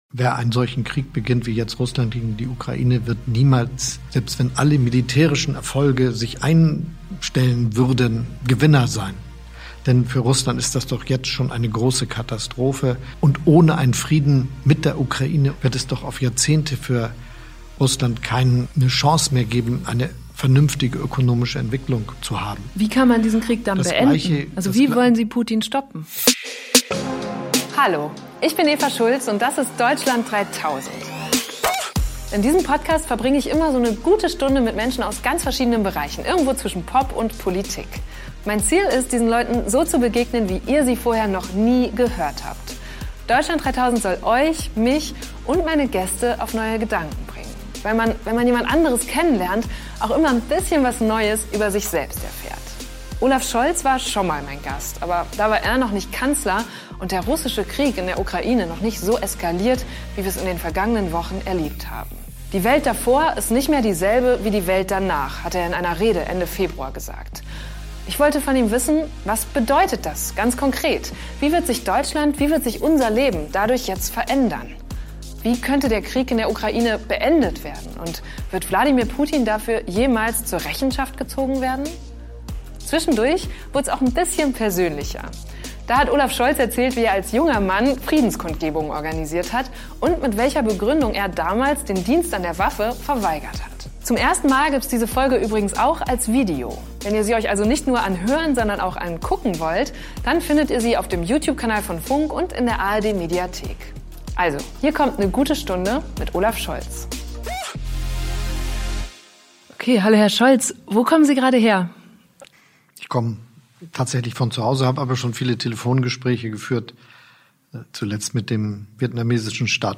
Olaf Scholz war schon mal mein Gast, aber da war er noch nicht Kanzler und der russische Krieg in der Ukraine noch nicht so eskaliert, wie wir es in den vergangenen Wochen erlebt haben.